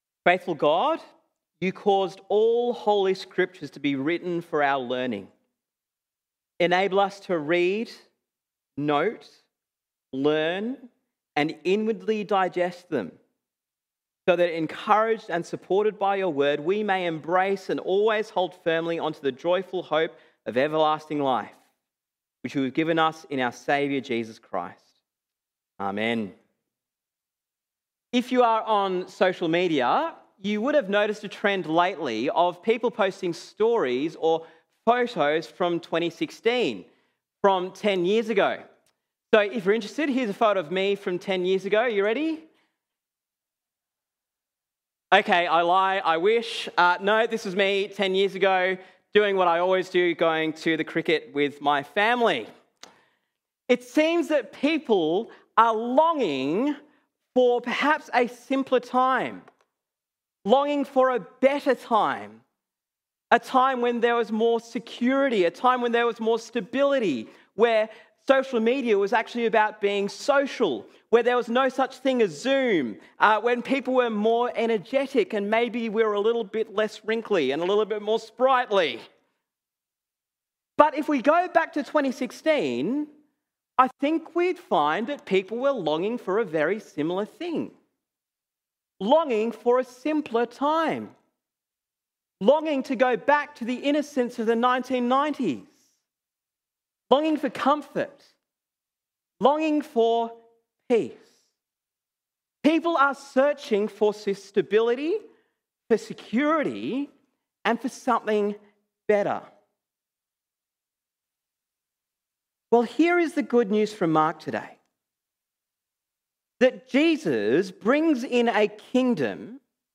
Sermon on Mark 1:14-45